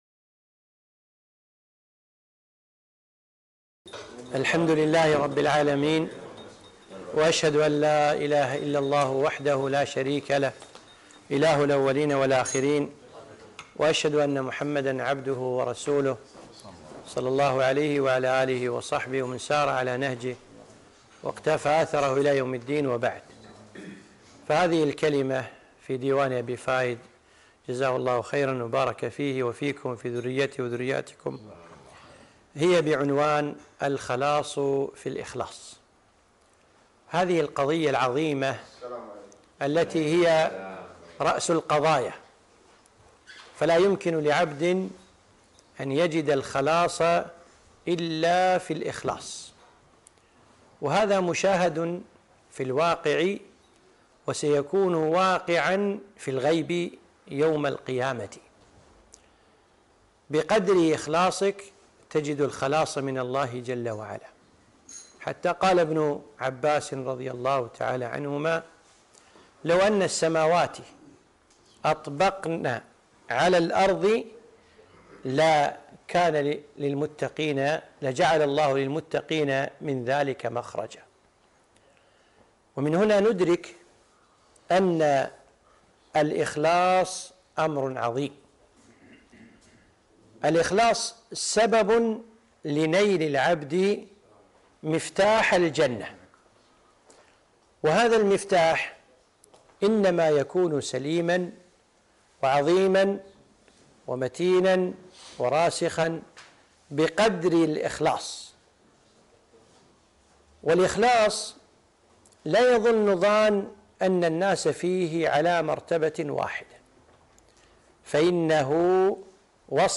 محاضرة - الخلاص في الإخلاص